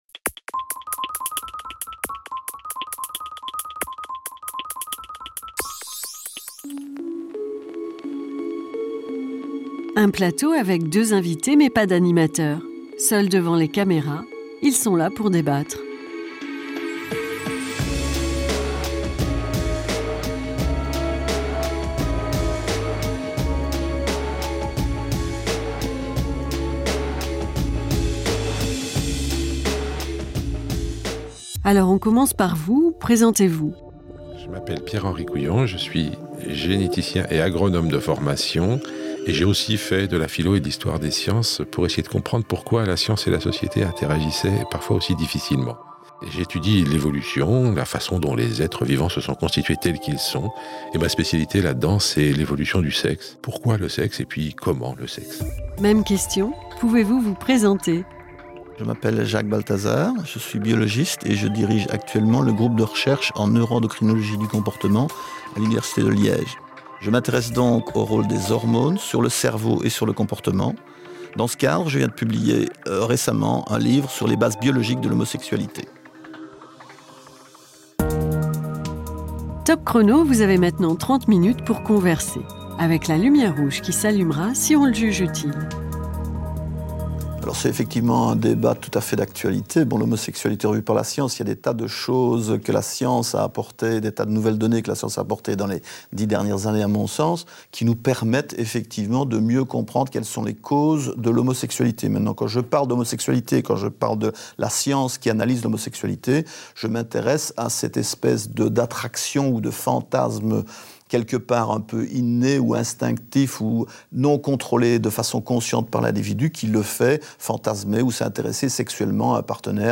Toutes les cultures sont-elles concernées par l'homosexualité ? Dans ce débat sans animateur, deux invités échangent leurs points de vue.